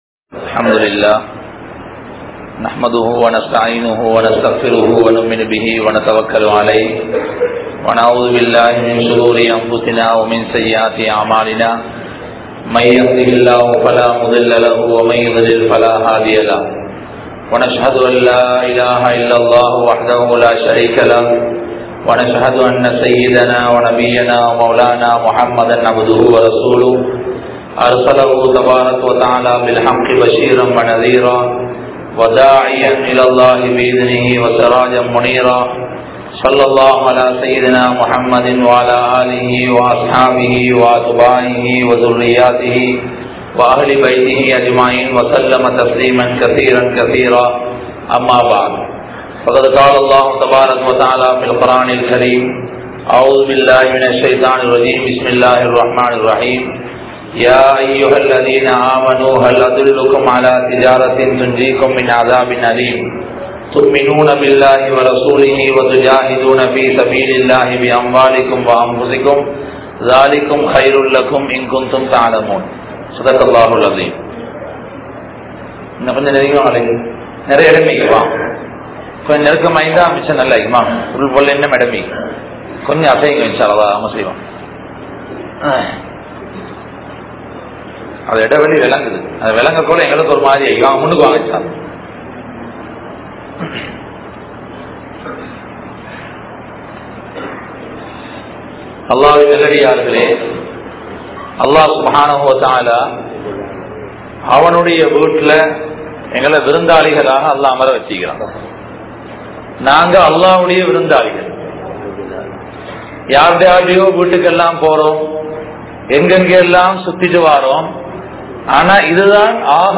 Ummath Pattriya Kavalai (உம்மத் பற்றிய கவலை) | Audio Bayans | All Ceylon Muslim Youth Community | Addalaichenai
Ambalathadi Masjidh